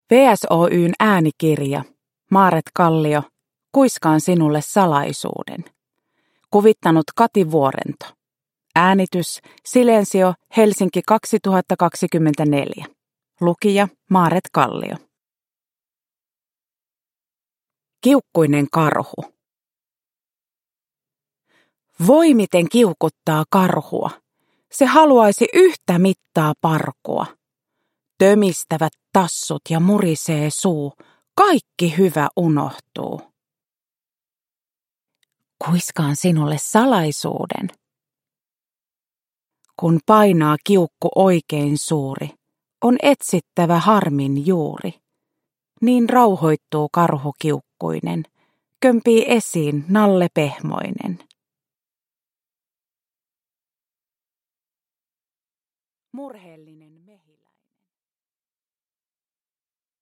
Kuiskaan sinulle salaisuuden (ljudbok) av Maaret Kallio | Bokon